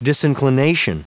Prononciation du mot disinclination en anglais (fichier audio)
Prononciation du mot : disinclination